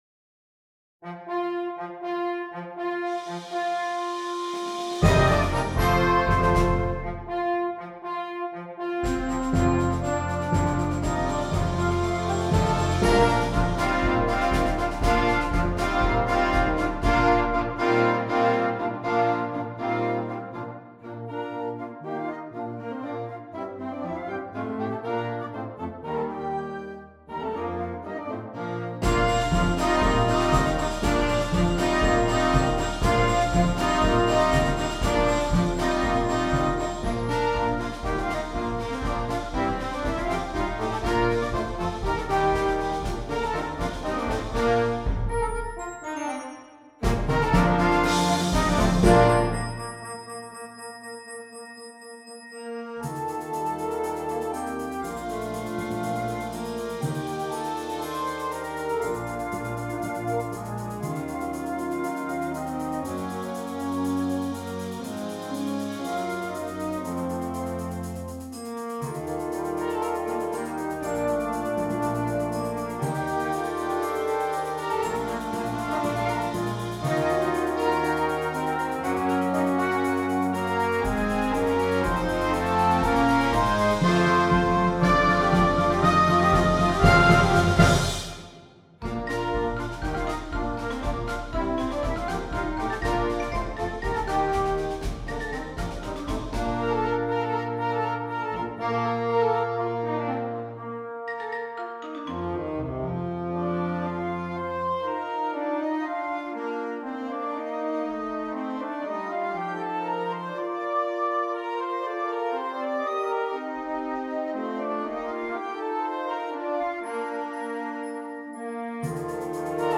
Flexible Band